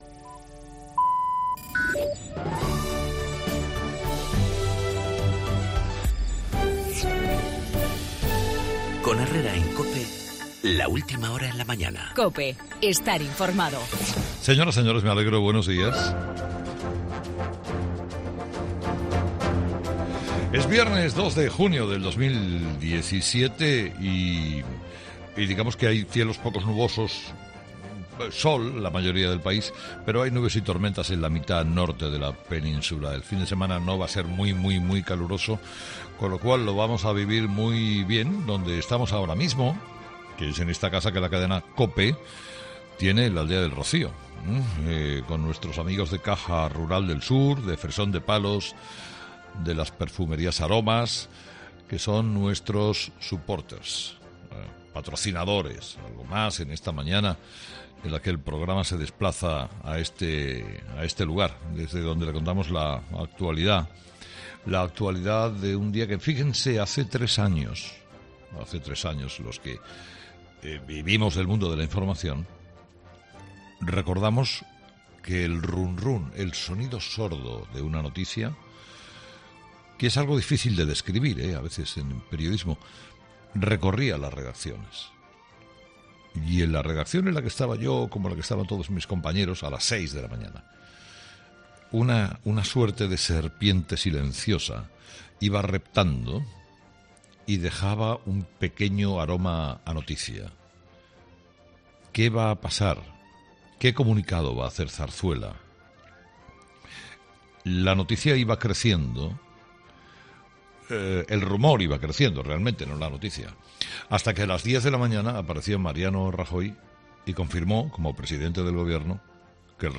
Carlos Herrera analiza en su monólogo la dimisión de Manuel Moix como fiscal jefe Anticorrupción tras conocerse su sociedad en Panamá